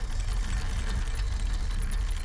reel_rotation.wav